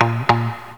RIFFGTR 08-R.wav